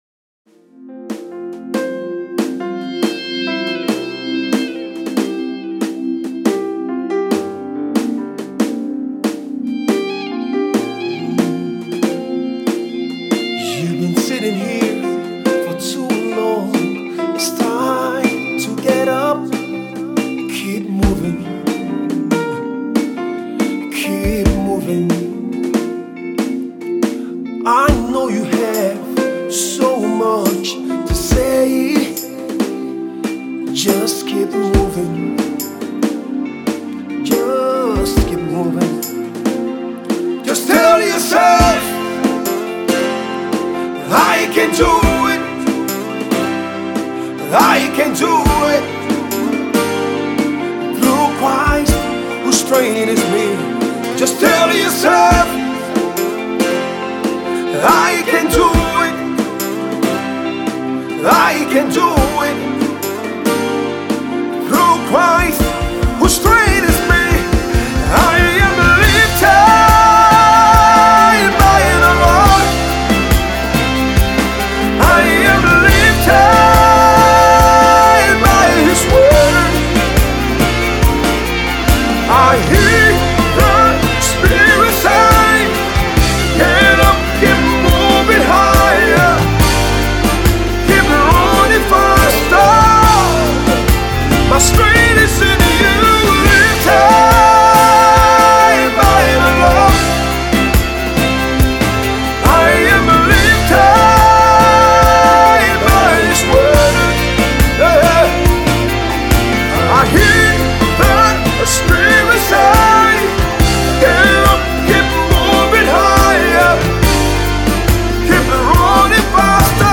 International multi-award winning gospel singer